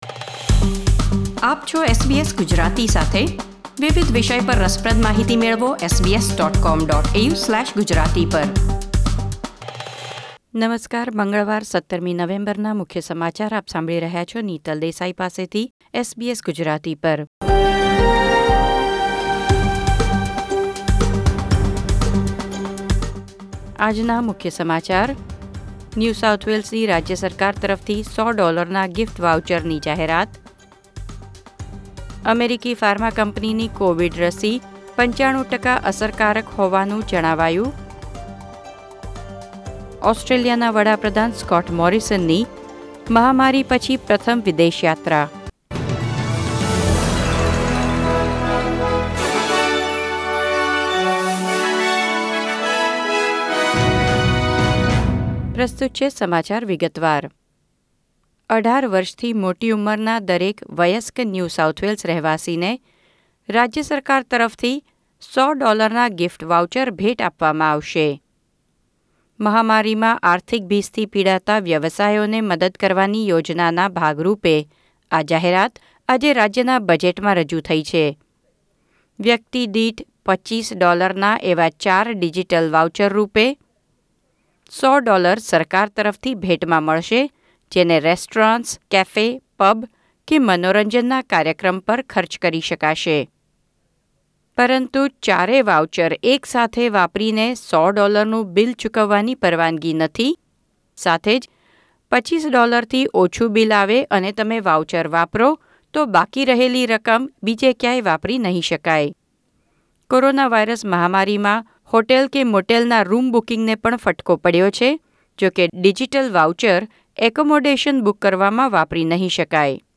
SBS Gujarati News Bulletin 17 November 2020